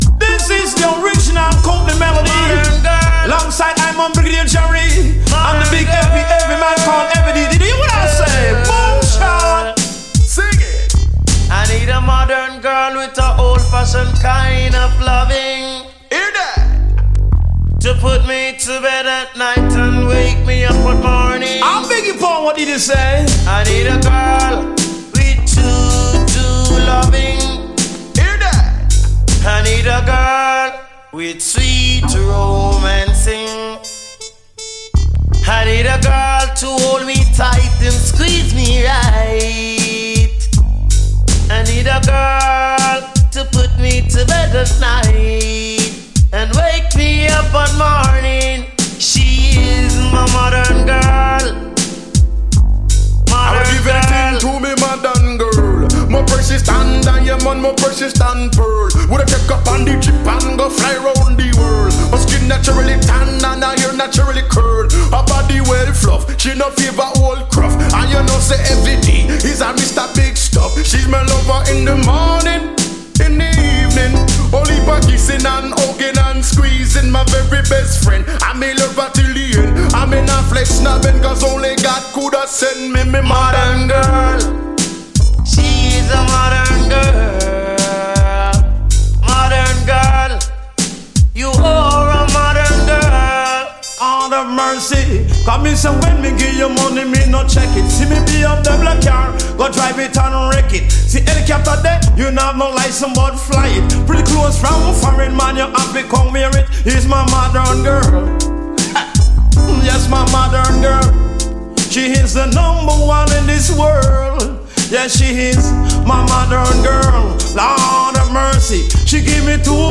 REGGAE
しっとりとアダルトでメロウなエレピ入りのリミックス